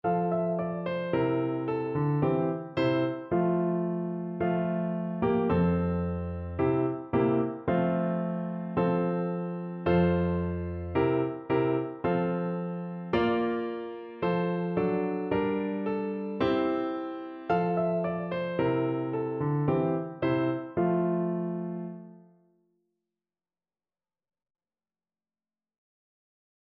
2/4 (View more 2/4 Music)
F4-F5
Fast =c.110
Traditional (View more Traditional Clarinet Music)